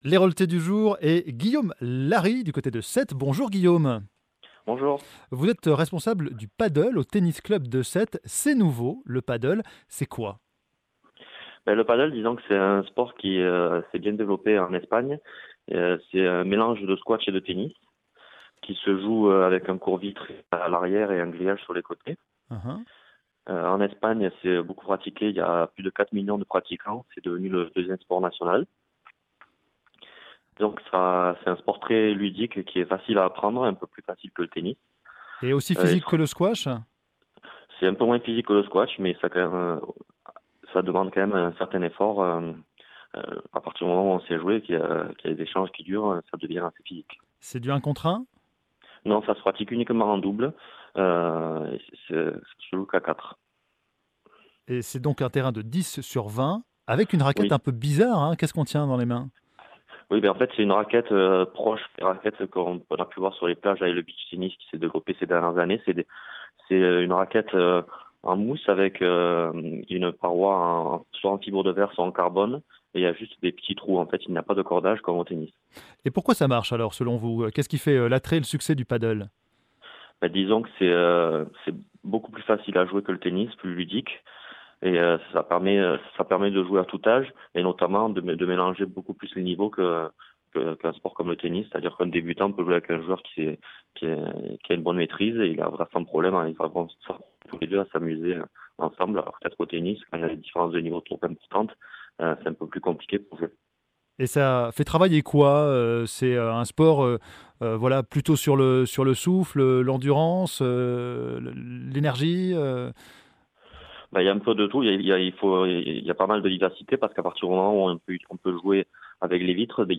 France Bleu Herault : Interview veille de l’inauguration